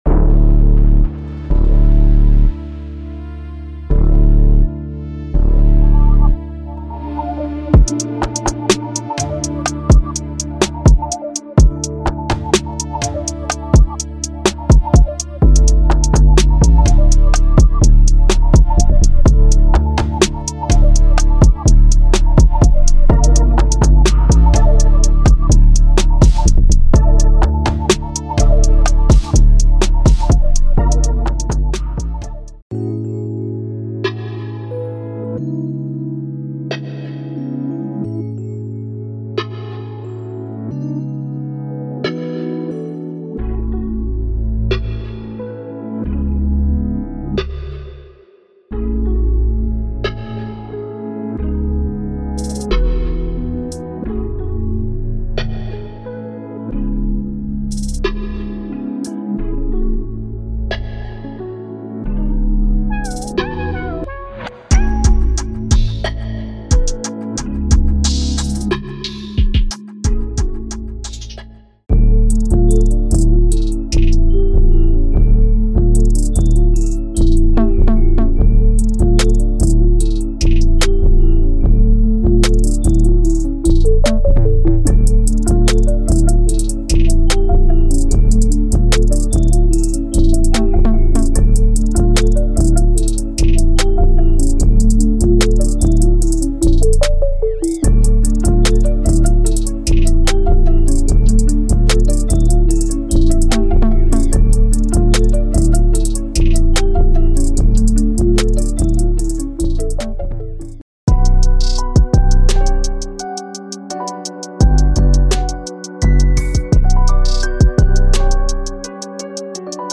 is a bundle pack mixed with Hip Hop,Trap and Trap Soul